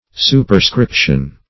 Superscription \Su`per*scrip"tion\, n. [L. superscriptio.